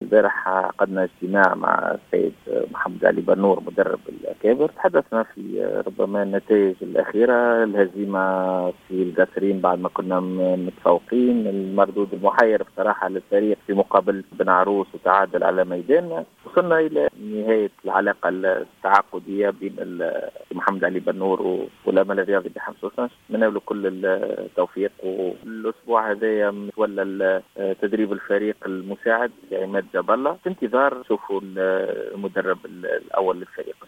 في تصريح لجوهرة اف